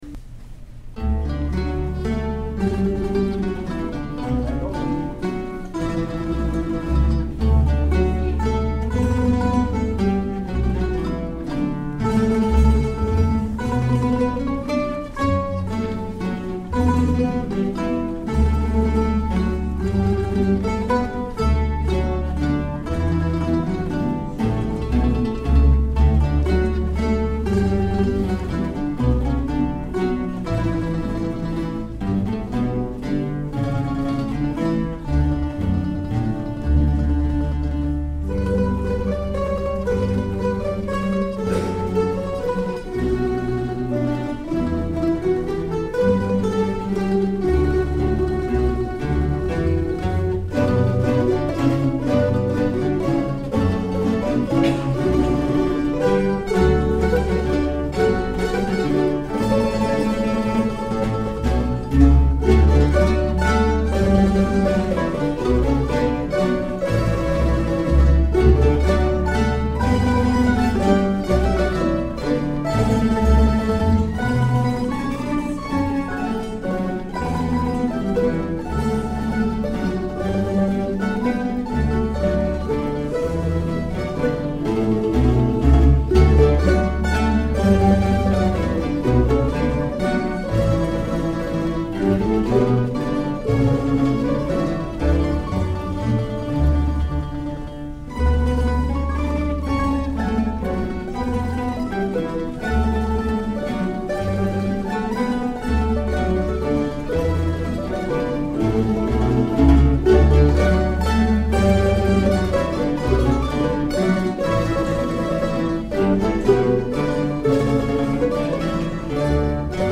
The Pittsburgh Mandolin Orchestra
performing these short arrangements of two of my tunes for mandolin orchestra at a concert in early October. These are great field recordings of a real American mandolin orchestra (nearly 40 members strong) at play.